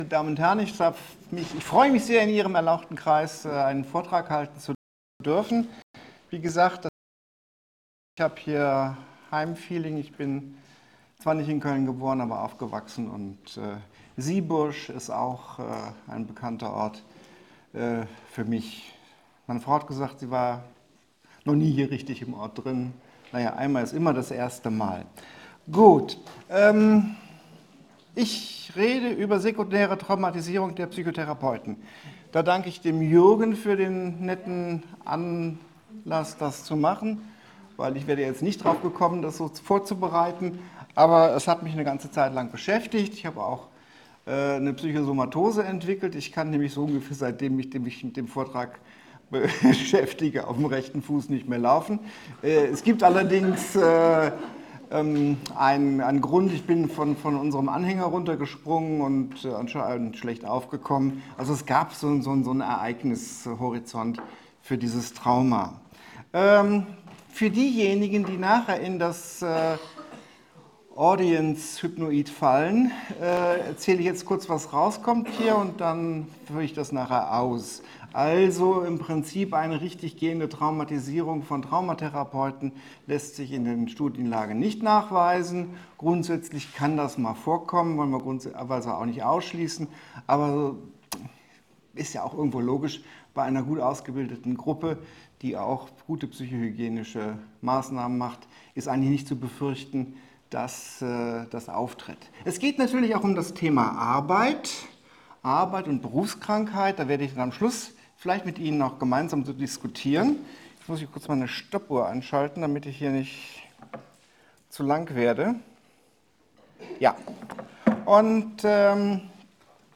Vortrag 25.05.2024, Siegburg: 29. Rheinische Allgemeine PSYCHOtheratietagung: Die Verantwortung der Suggestion - inszenierte Zuversicht